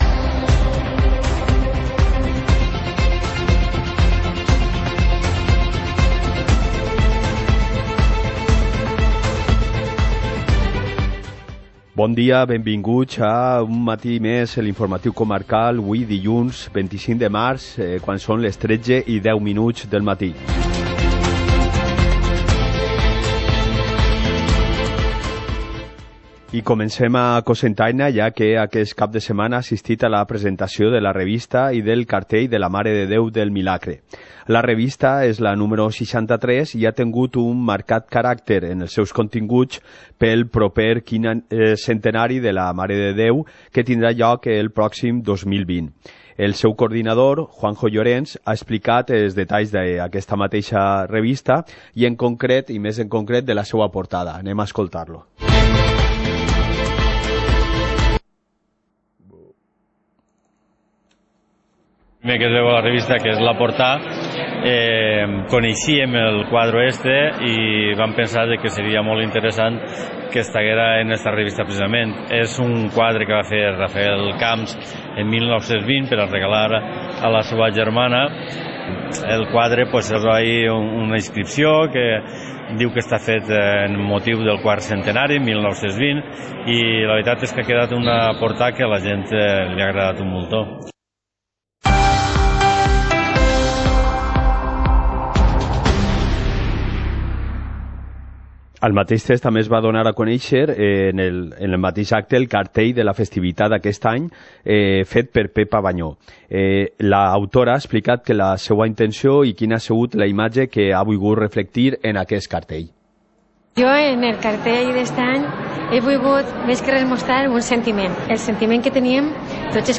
Informativo comarcal - lunes, 25 de marzo de 2019